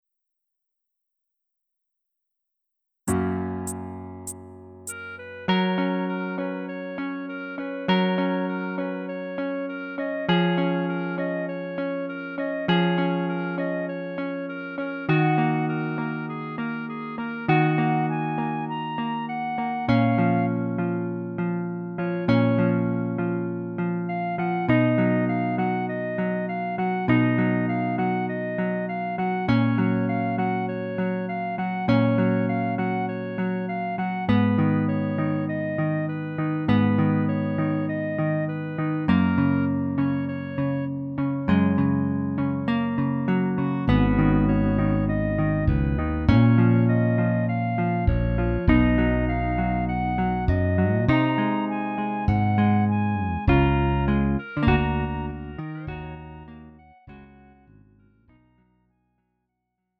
음정 -1키 3:23
장르 가요 구분 Lite MR